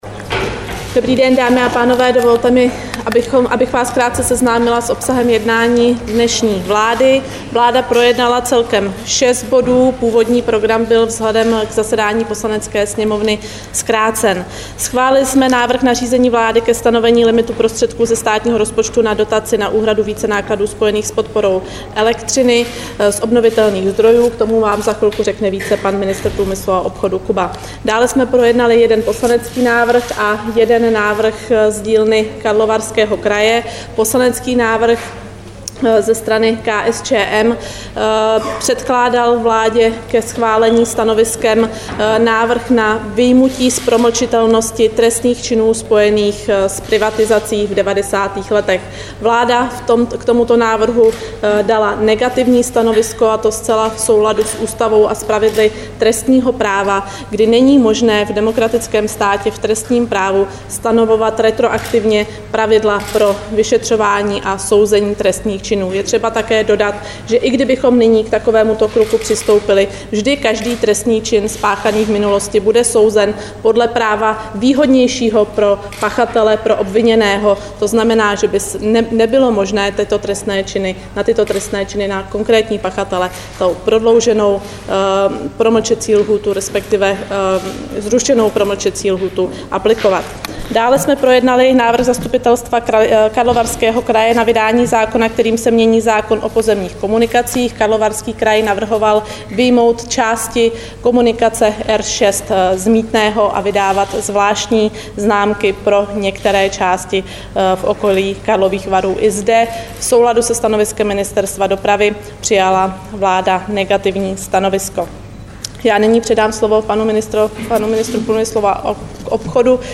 Tisková konference po jednání vlády, 24. října 2012